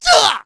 Ezekiel-Vox_Attack1.wav